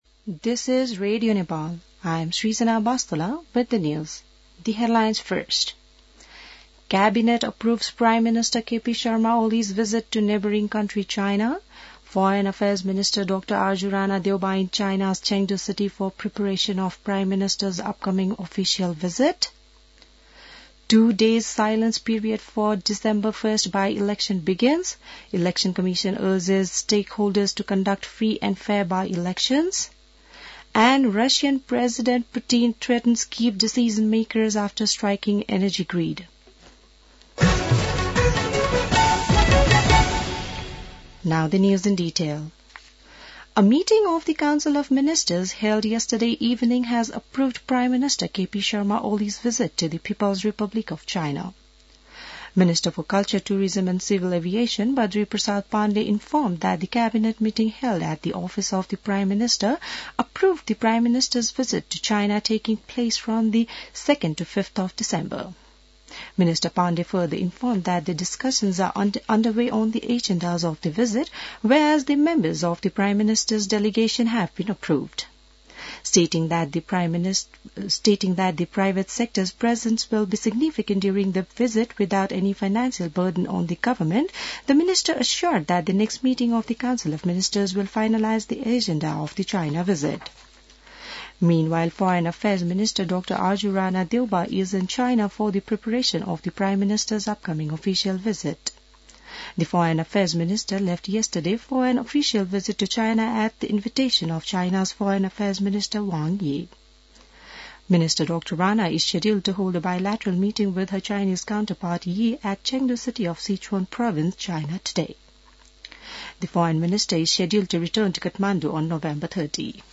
बिहान ८ बजेको अङ्ग्रेजी समाचार : १५ मंसिर , २०८१